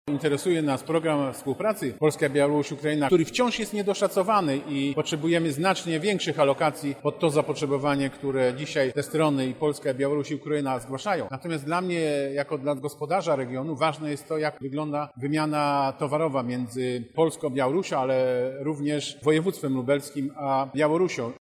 W ramach Targów Energetycznych ENERGETICS w Lublinie odbywa się Forum Gospodarcze DOBROSĄSIEDZTWO – 2017.
O współpracy Polski ze wschodnimi sąsiadami mówi Sławomir Sosnowski, Marszałek Województwa Lubelskiego.